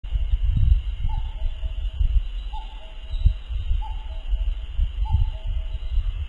声　　　：カッコウ、カッコウを繰り返し、カッカッコウと鳴くこともある。ゴアゴアとかピピピピとも鳴く。
鳴き声１
kakkou01.mp3